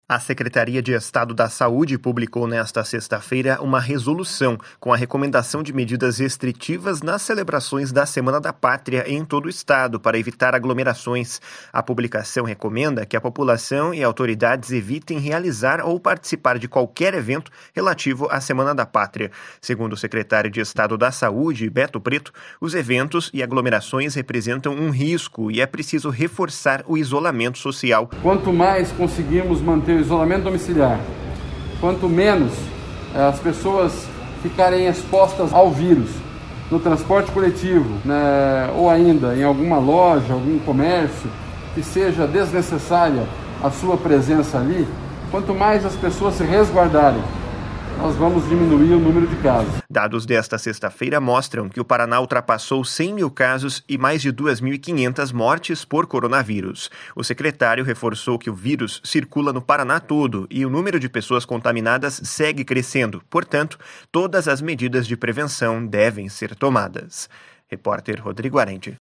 // SONORA BETO PRETO // Dados desta sexta-feira mostram que o Paraná ultrapassou 100 mil casos e mais de 2.500 mortes por coronavírus.